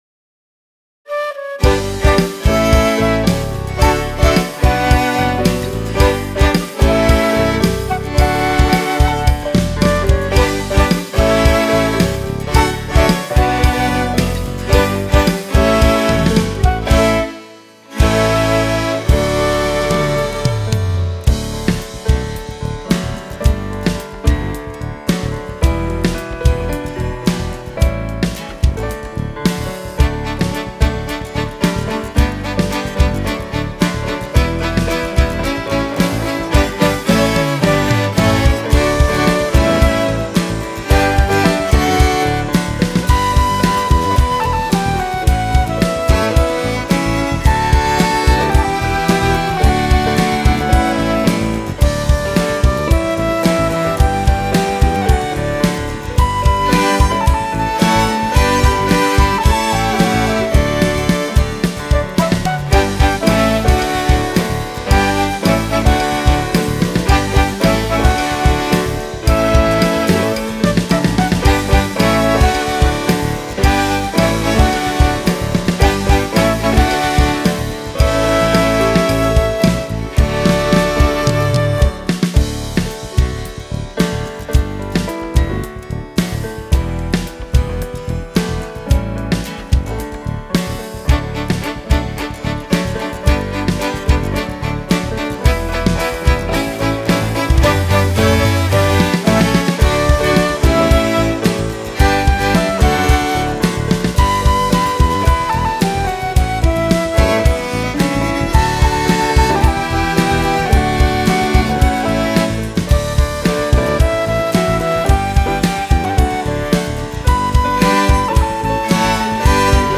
Wersja instrumentalna